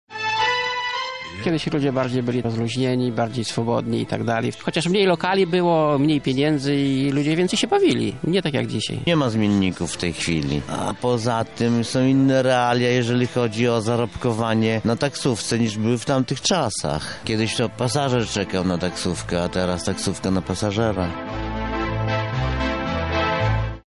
Zapytaliśmy lubelskich taksówkarzy, czy rzeczywistość serialu ma coś wspólnego z obecną.